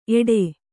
♪ eḍe